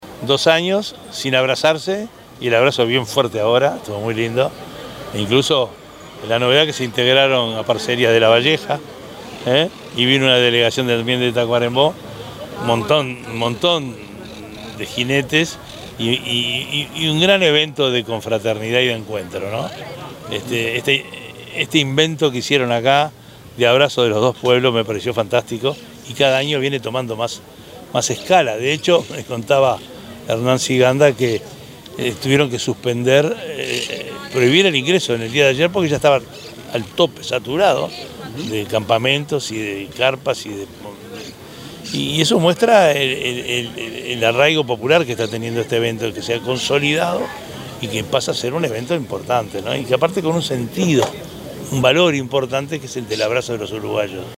enrique_antia_intendente_de_maldonado.mp3